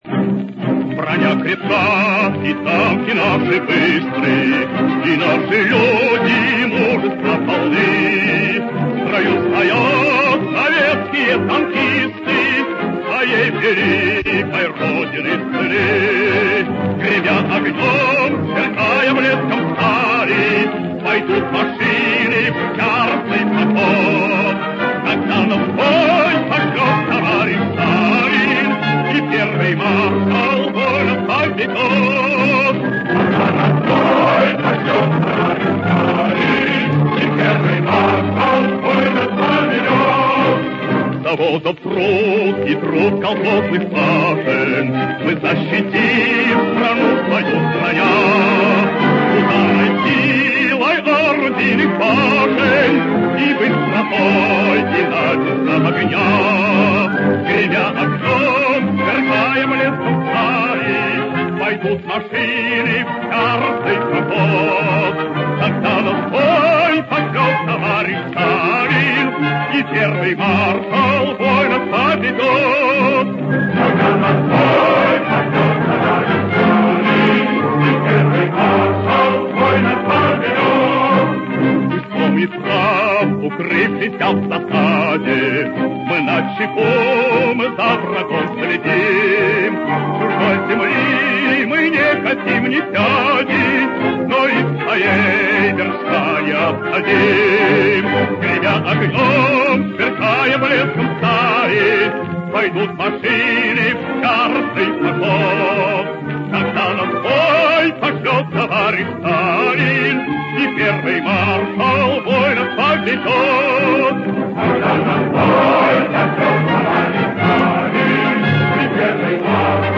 Военные марши (.MP3) [22]